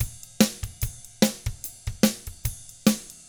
146ROCK T2-R.wav